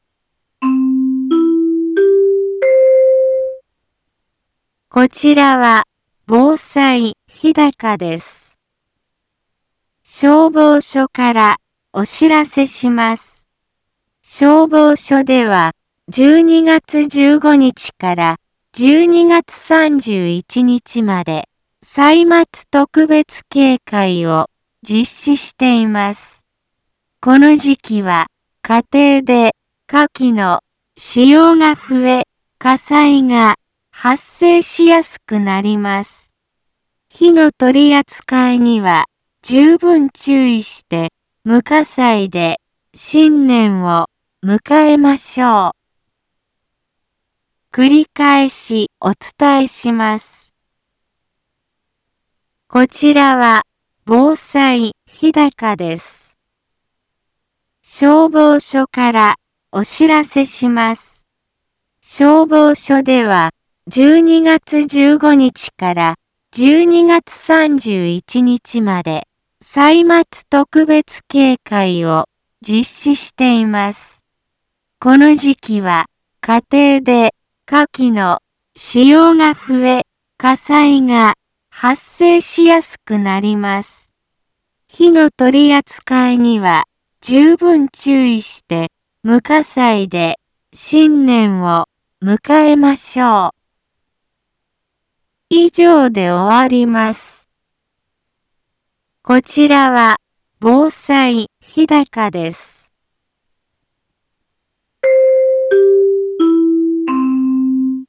一般放送情報
Back Home 一般放送情報 音声放送 再生 一般放送情報 登録日時：2018-12-17 15:04:01 タイトル：歳末特別警戒について インフォメーション：こちらは、防災日高です。